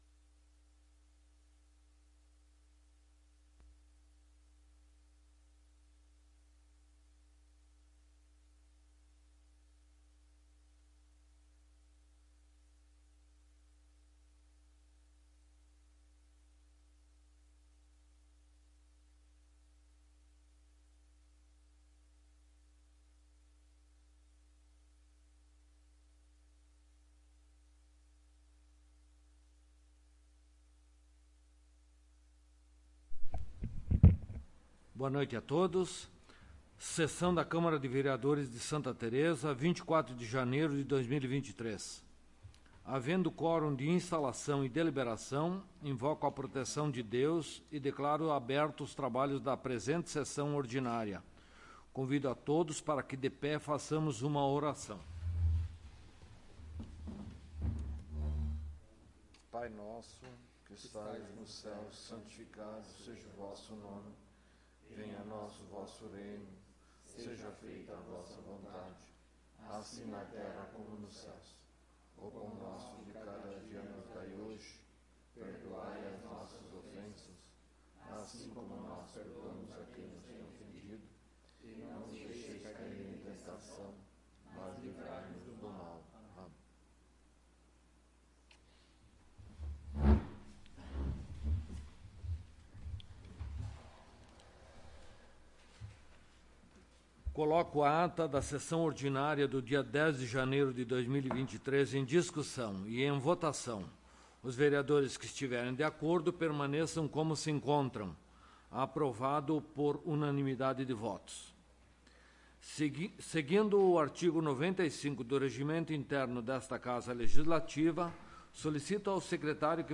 Áudio da Sessão
Local: Plenário Pedro Parenti